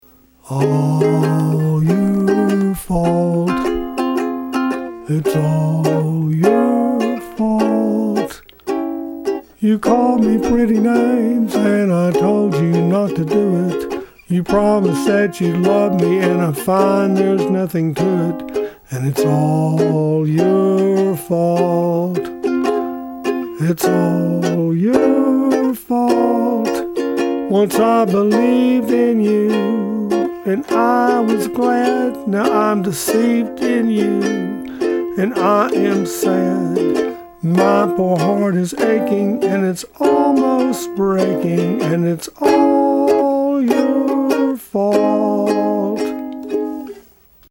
Ukulele mp3 songs from sheet music
Please ignore any sour notes.